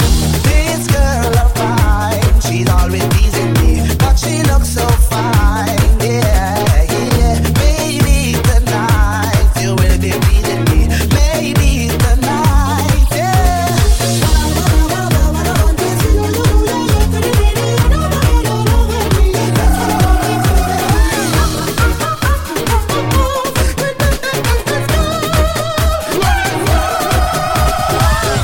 Genere: dance,disco,pop,house,afro,hit,remix